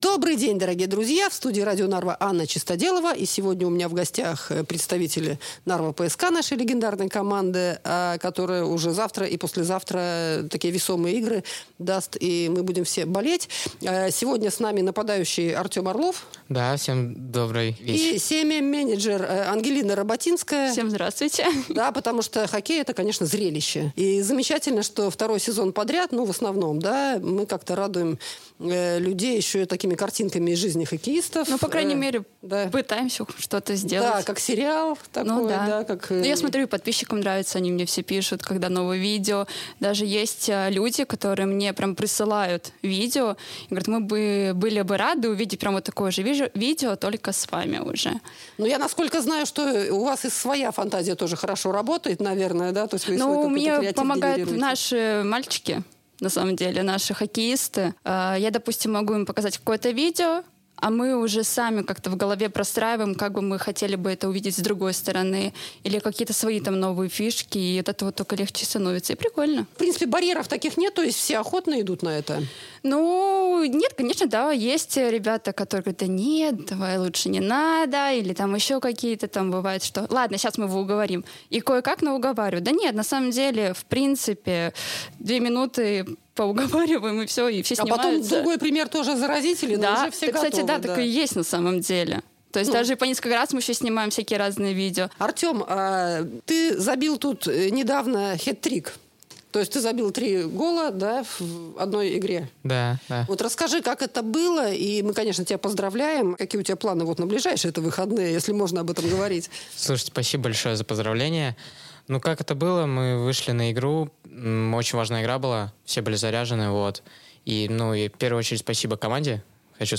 О том, чем планируют удивить болельщиков уже в эти субботу и воскресенье, они рассказали в эфире Radio Narva.